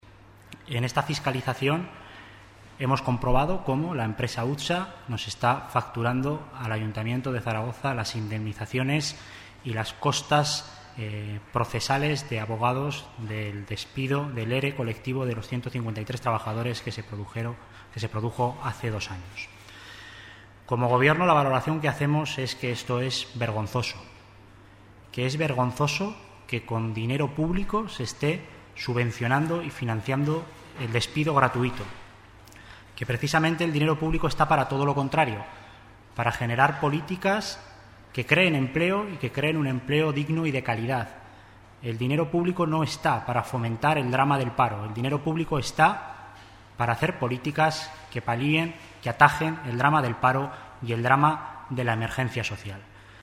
Así lo ha explicado en rueda de prensa el Consejero de Servicios Públicos y Personal, Alberto Cubero, quien ha calificado de "vergonzosa" esta situación y ha dicho que "este Gobierno no admite el pago de despidos con dinero público, en una decisión adoptada, además, en el último minuto de la anterior legislatura".